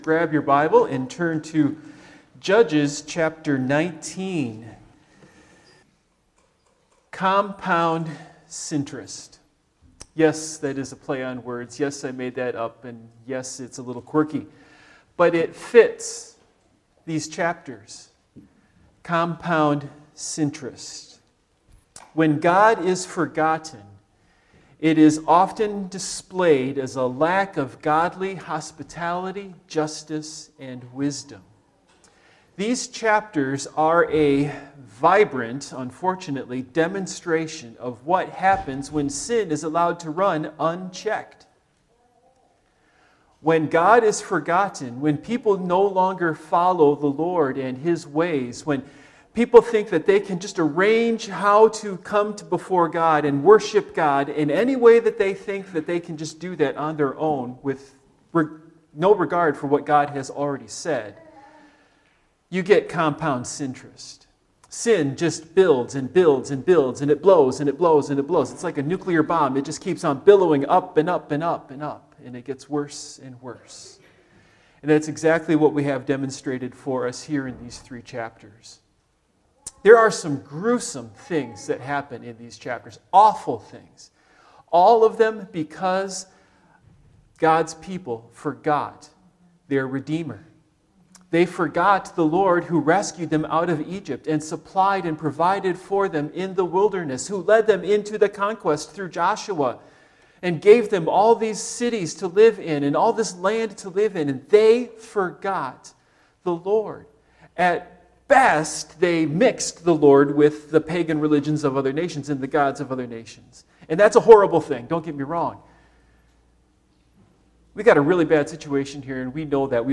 Judges Passage: Judges 19-21 Service Type: Morning Worship Topics